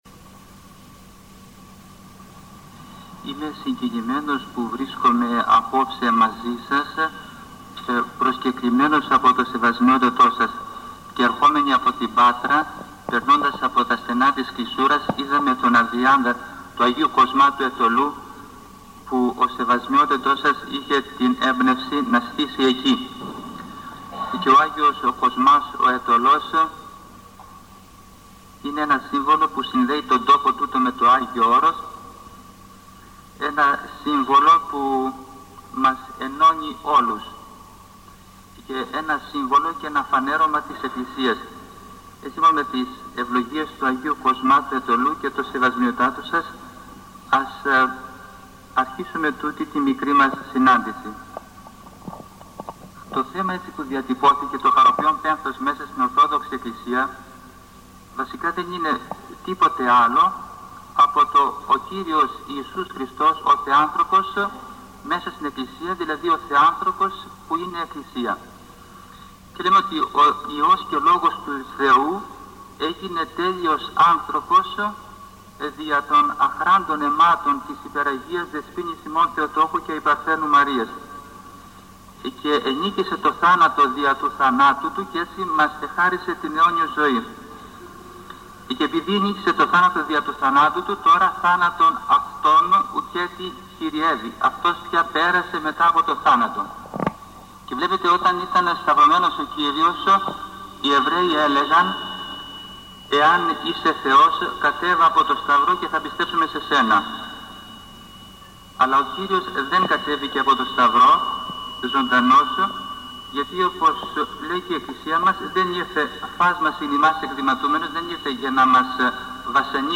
Ομιλία του π. Βασιλείου του Ιβηρίτη